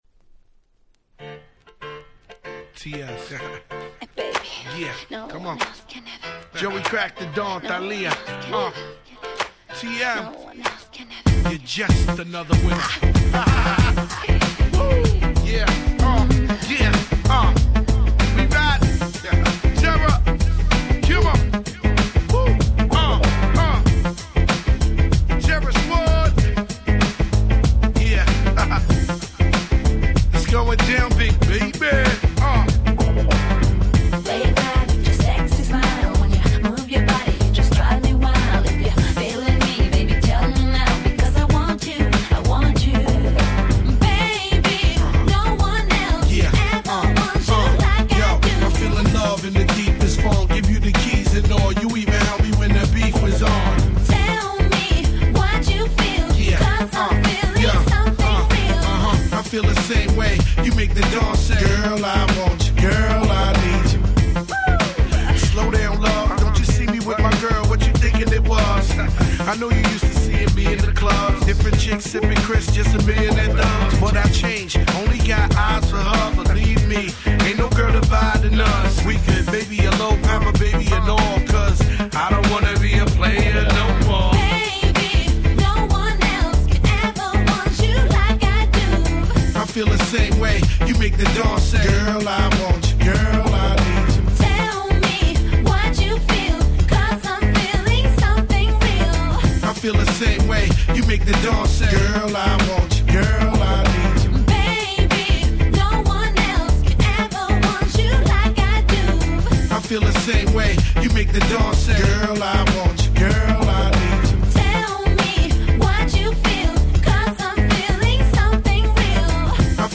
White Press Only Remix !!